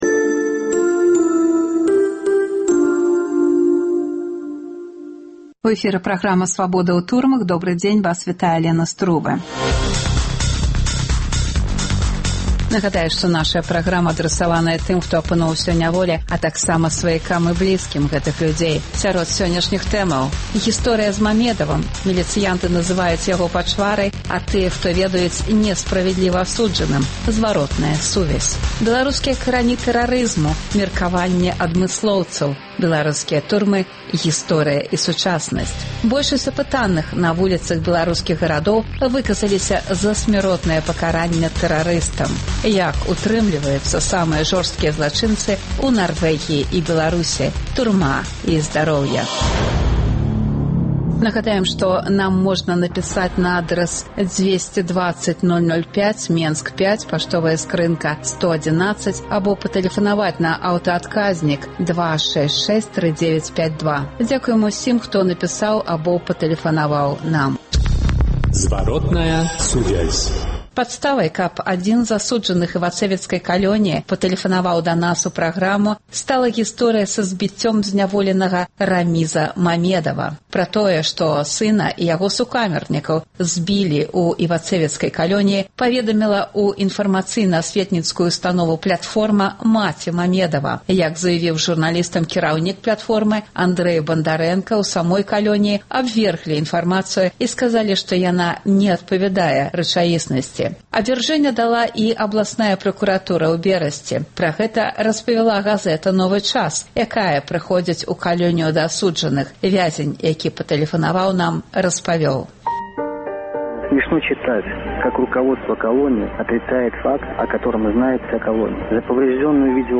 Апытаньні на вуліцах беларускіх гарадоў.